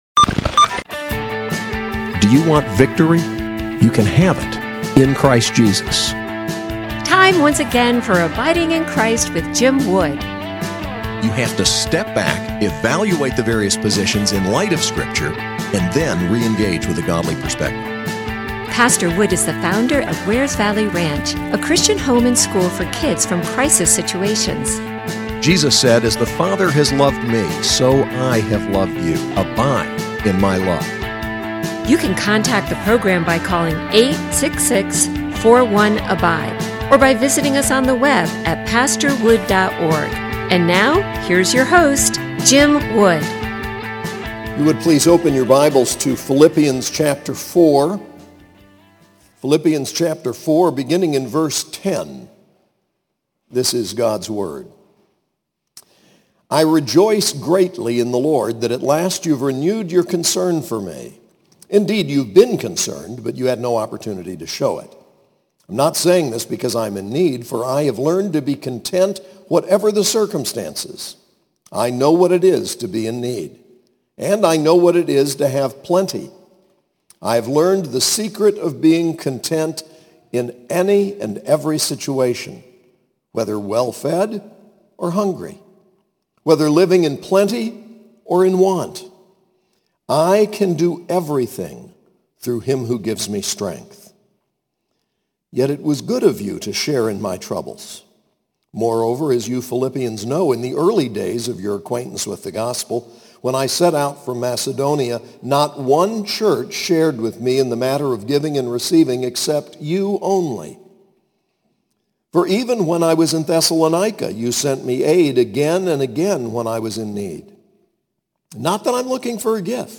SAS Chapel: Philippians 4:10-23